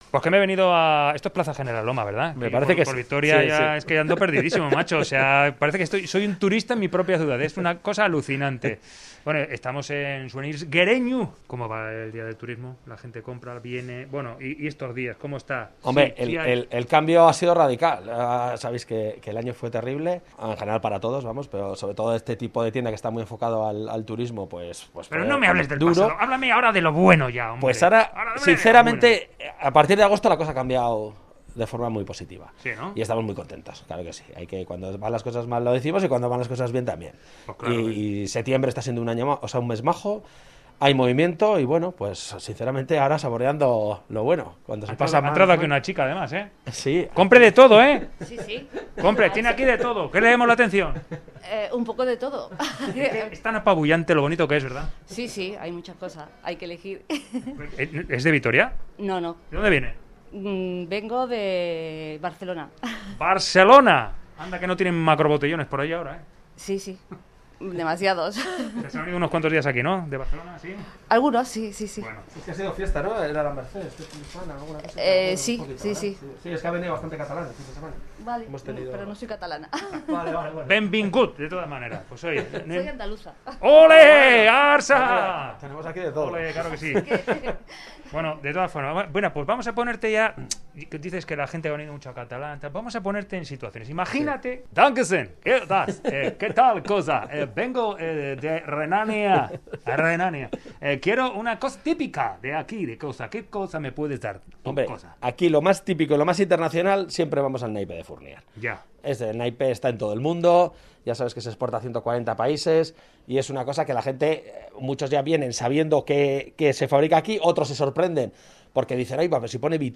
Audio: Reportaje: 'Dame algo típico de aquí'